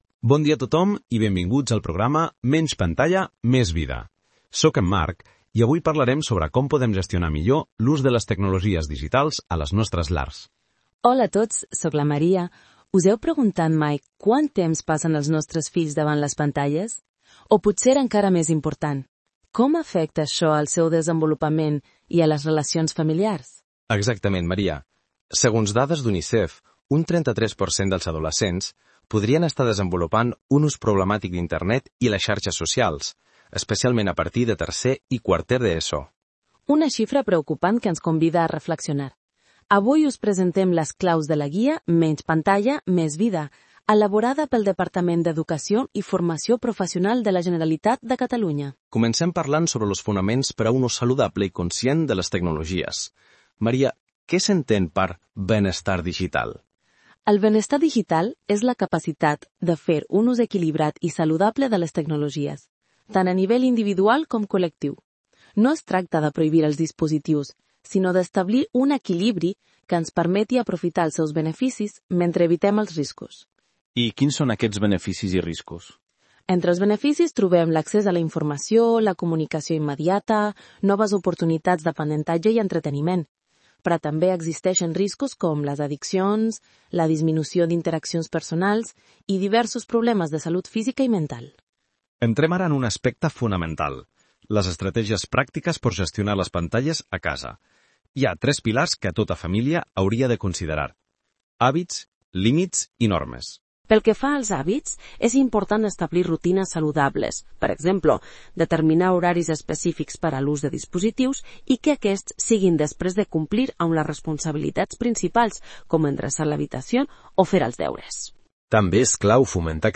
Segurament, moltes famílies ja hi heu donat un cop d’ull i teniu una idea del seu contingut, però per facilitar-vos l’accés a la informació del Pla de Digitalització Responsable hem generat, gràcies a la intel·ligència artificial, un